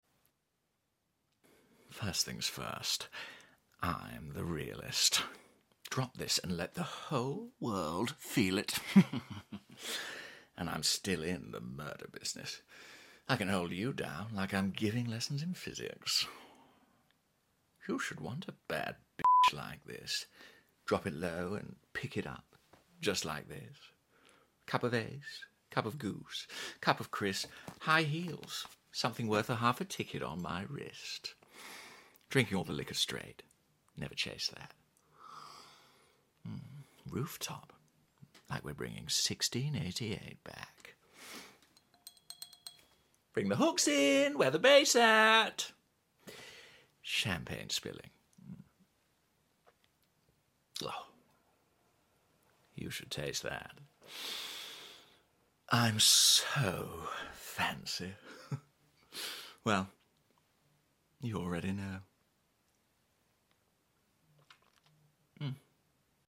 🎭 Dramatic Monologue: Fancy By Sound Effects Free Download